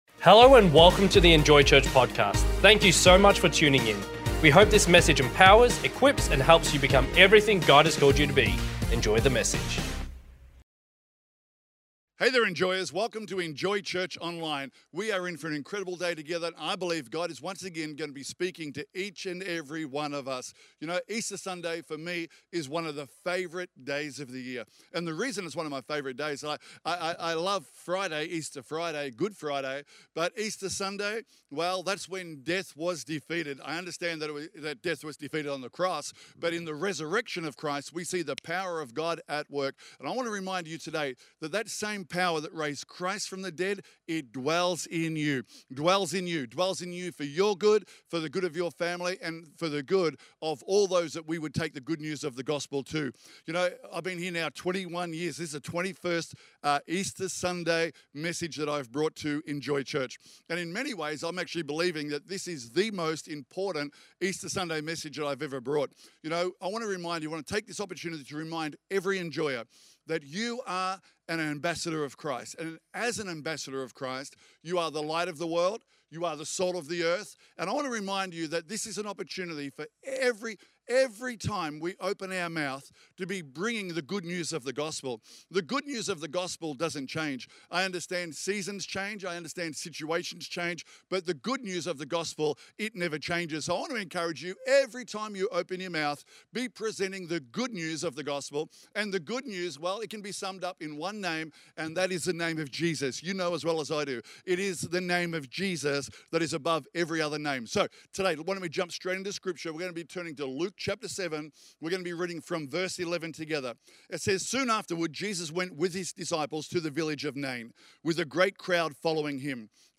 Online-Preaching-12th-April.mp3